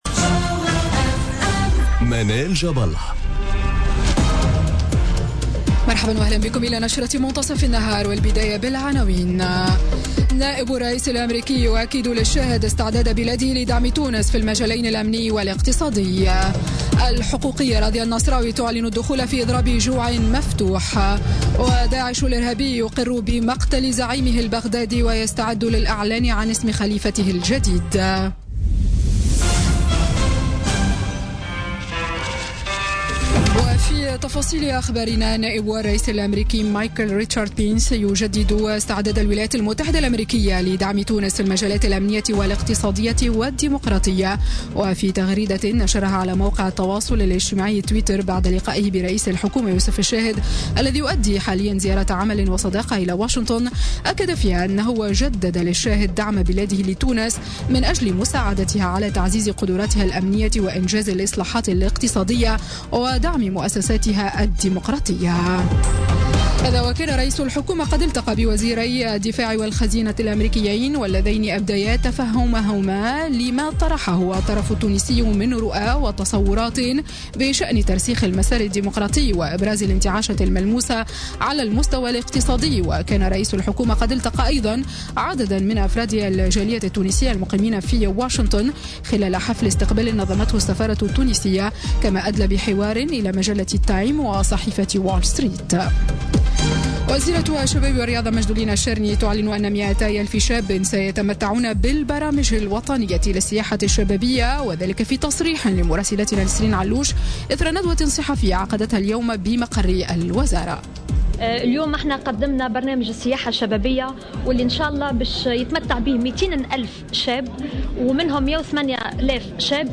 نشرة أخبار منتصف النهار ليوم الثلاثاء 11 جويلية 2017